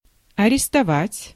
Ääntäminen
US
IPA : /əˈɹɛst/